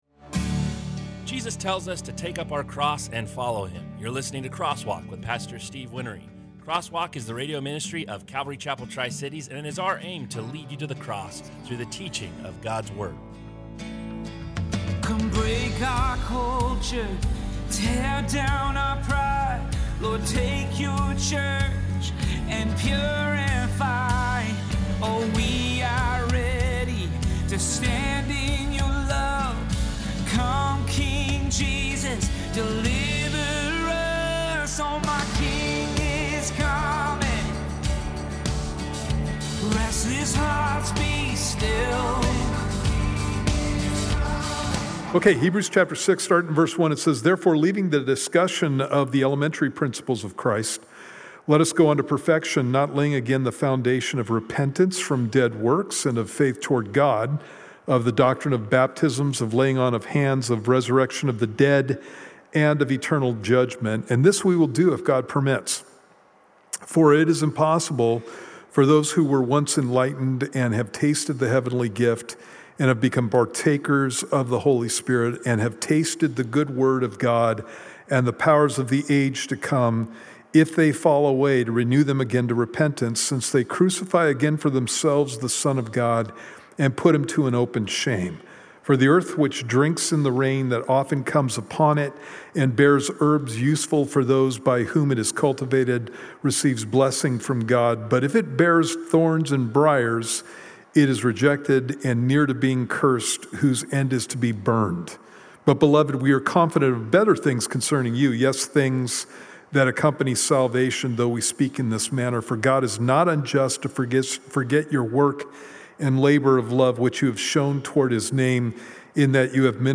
Crosswalk is a verse by verse bible study.